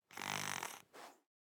minecraft / sounds / mob / fox / sleep3.ogg